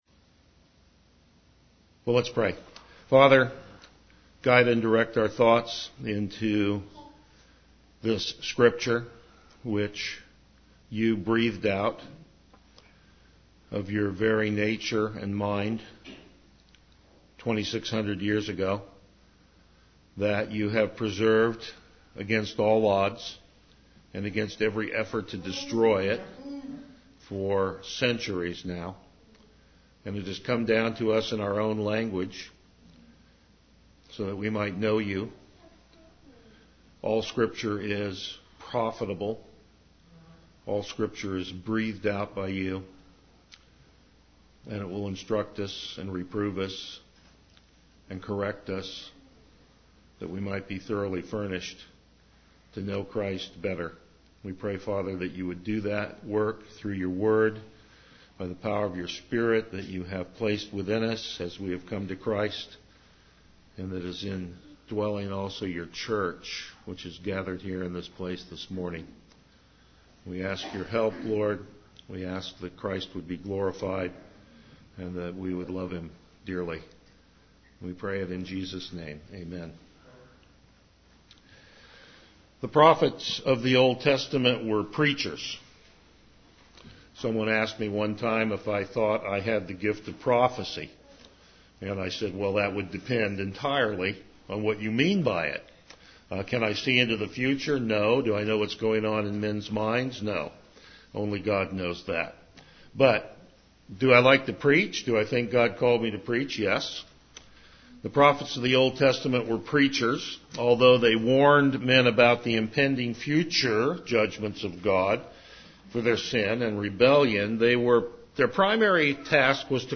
Passage: Habakkuk 1:1-17 Service Type: Morning Worship
Verse By Verse Exposition